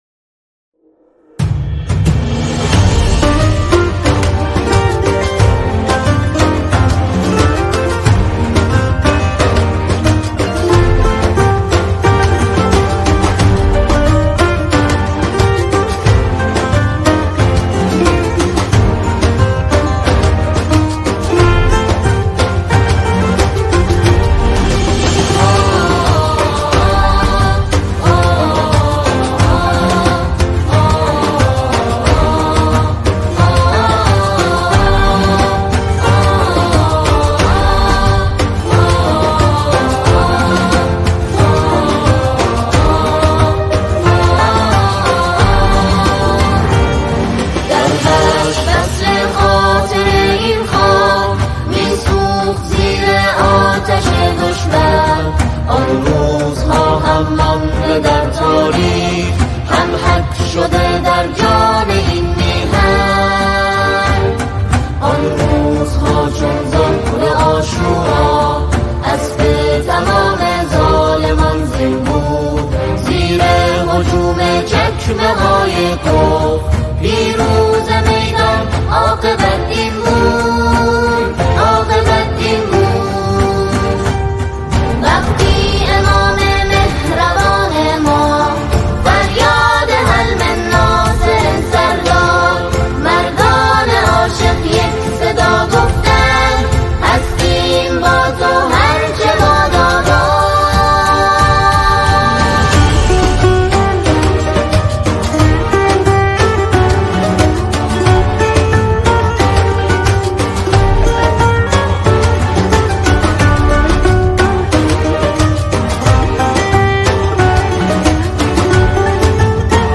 نماهنگ زیبای دفاع مقدس